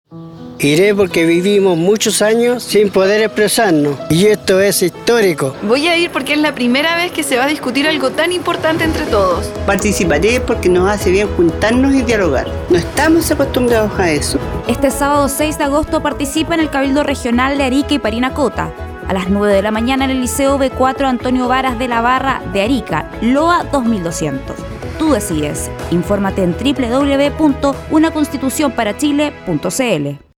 Audio promoción testimonial con detallada información de hora y lugar de realización de los cabildos regionales, Región de Arica y Parinacota.